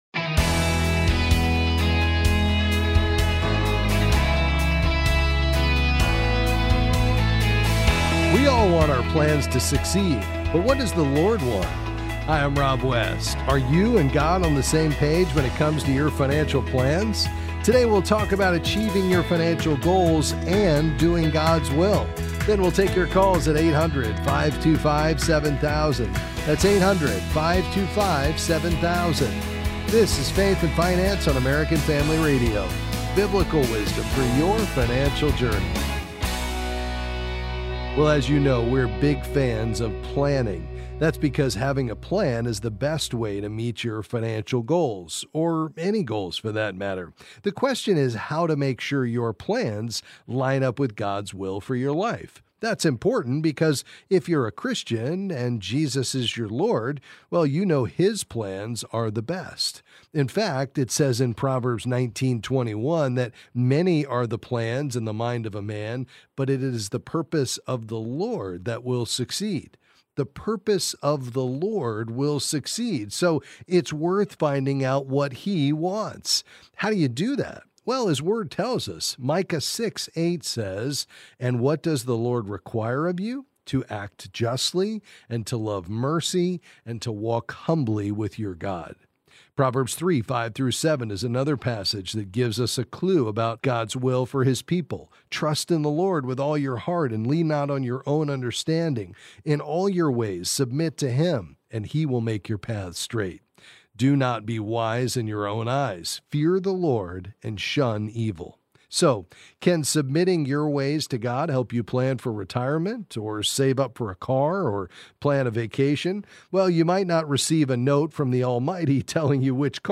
Then he’ll answer your calls about various financial topics.